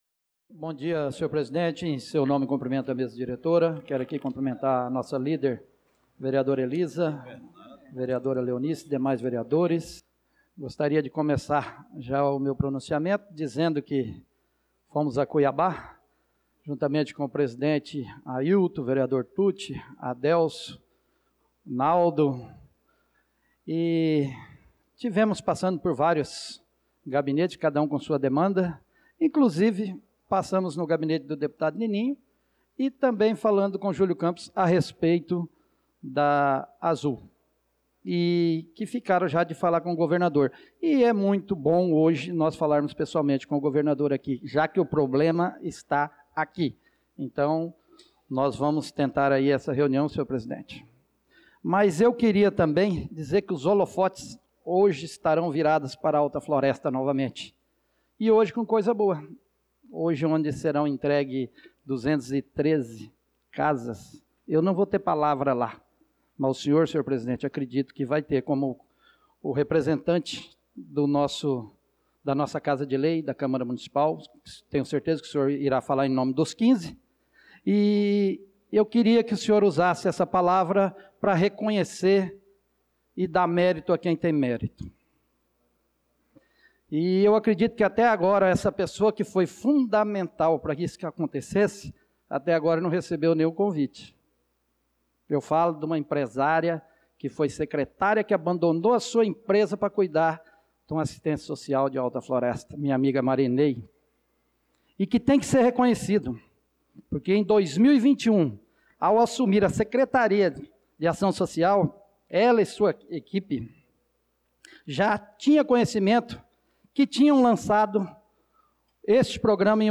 Pronunciamento do vereador Marcos Menin na Sessão Ordinária do dia 02/06/2025